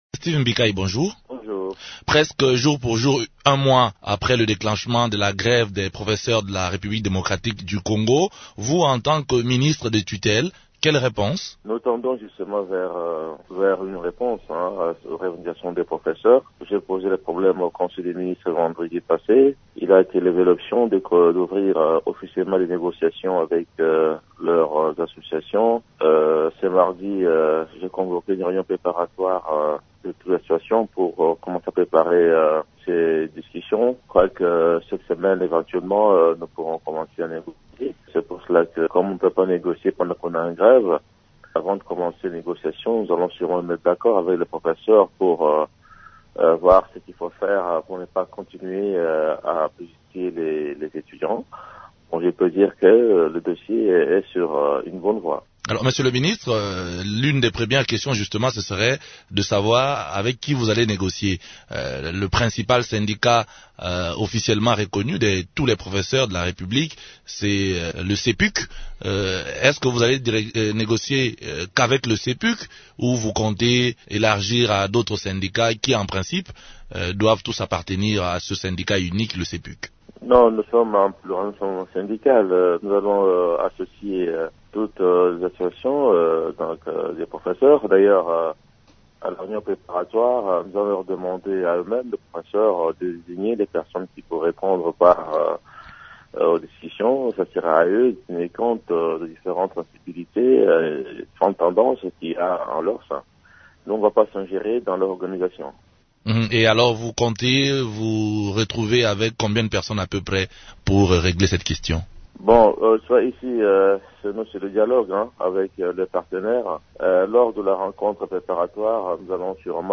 Les négociations sont ouvertes avec les associations des professeurs, a affirmé le ministre de l’Enseignement supérieur et universitaire (ESU), Steve Mbikayi. Il est l’invité de Radio Okapi du mardi 3 octobre.